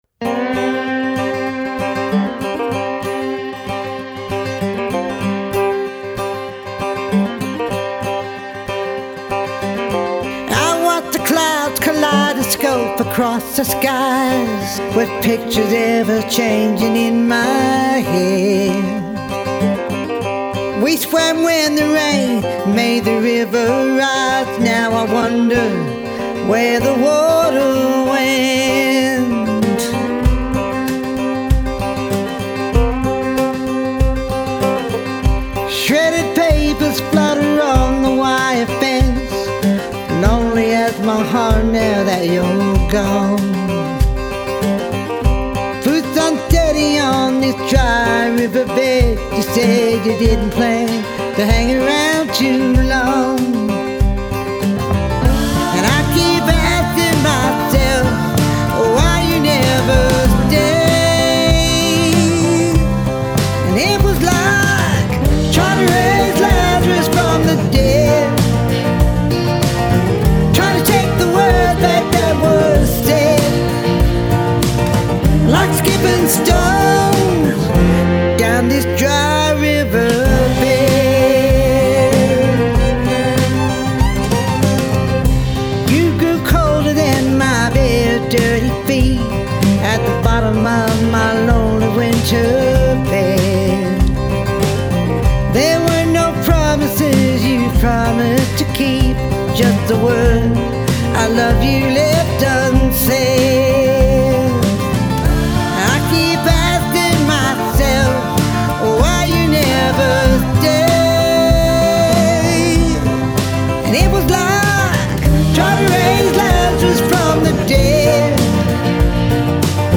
backing vocals